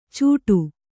♪ cūṭu